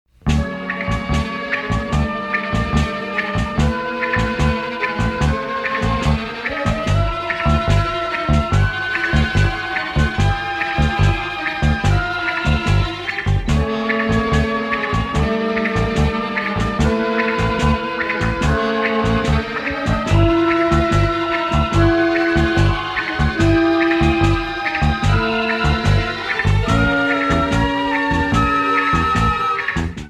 monaural sound from master tapes